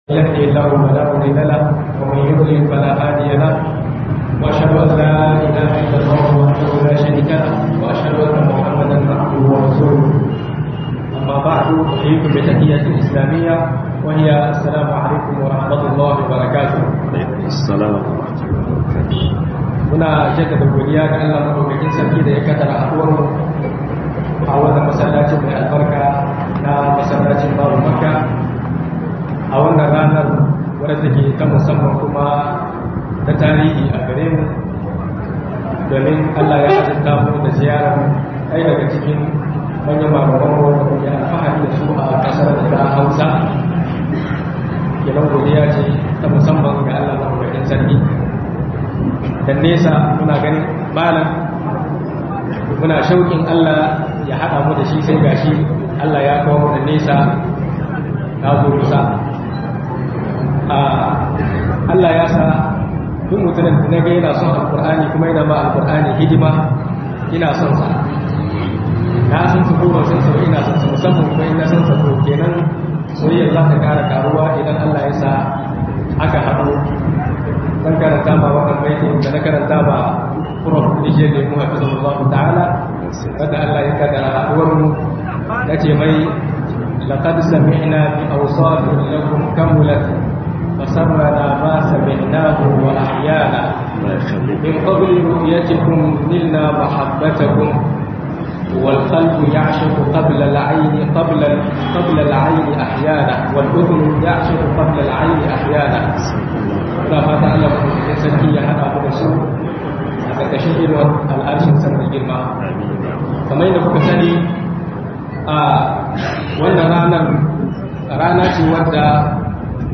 Mahimmancin sanin Tafsiri - MUHADARA daura Niamey du 5 - 9 nov 2025